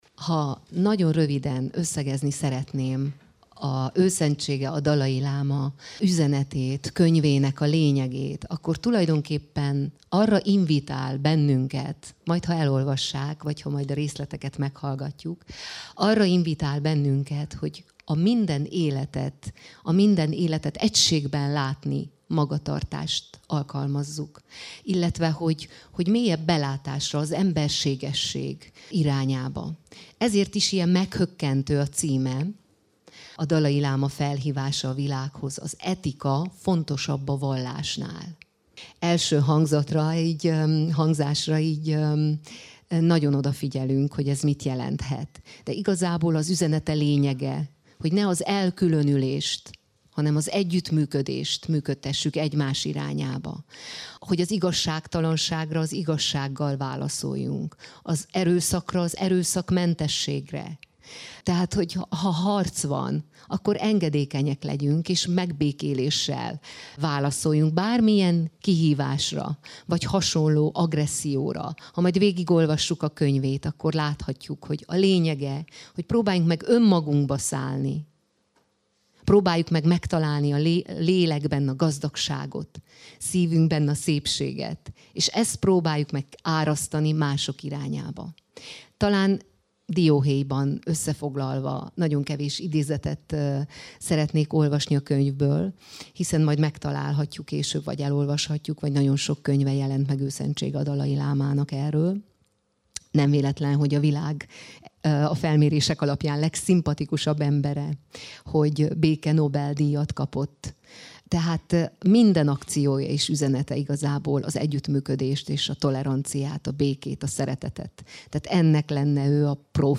Dalai Láma könyvbemutató a Marosvásárhelyi Rádióban - Marosvasarhelyi Radio
A várakozás izgalma, harmóniát sugalló dallamok, a füstölgő által kiváltott elemeltség érzet, a vetítőn a dalai láma mélyről sugárzó mosolya és békesség lengte be tegnap délután a Marosvásárhelyi Rádió 3-as stúdióját.